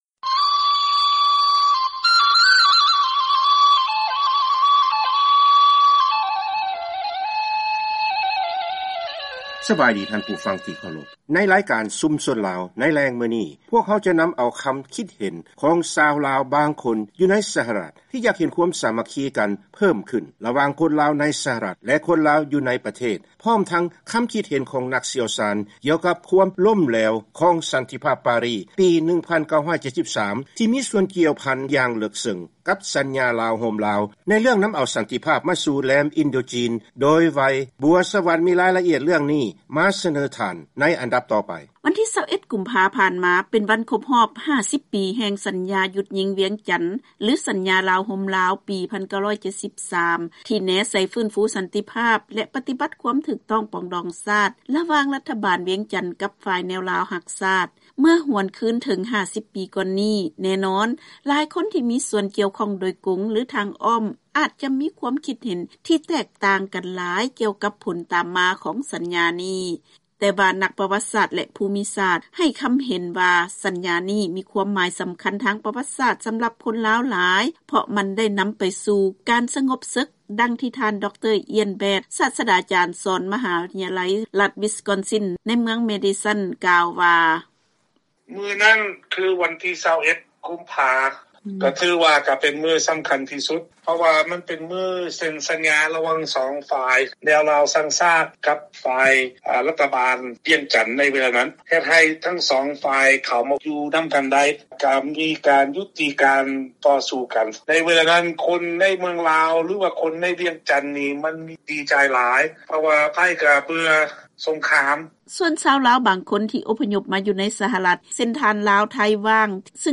ເຊີນຟັງການສຳພາດກັບຄົນລາວ ແລະນັກປະຫວັດສາດໃນ ສຫລ ກ່ຽວກັບສັນຍາປອງດອງຊາດ ປີ 1973